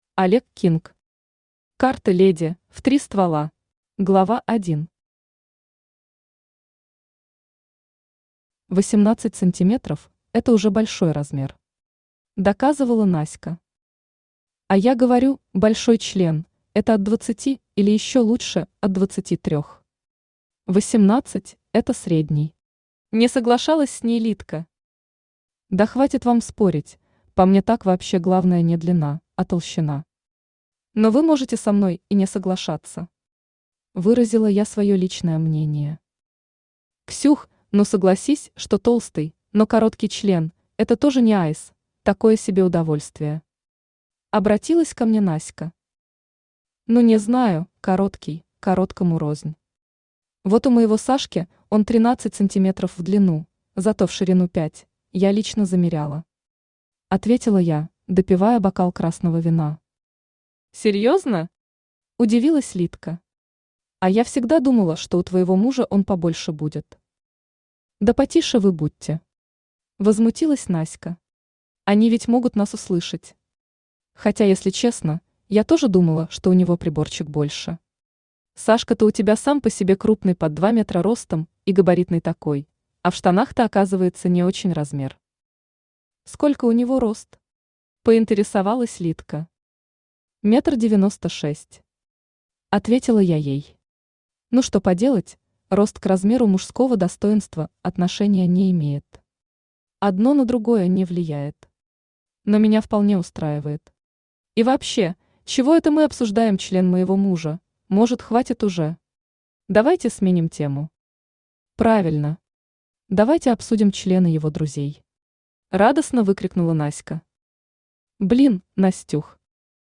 Аудиокнига Карты, леди, в три ствола | Библиотека аудиокниг
Aудиокнига Карты, леди, в три ствола Автор Олег Кинг Читает аудиокнигу Авточтец ЛитРес.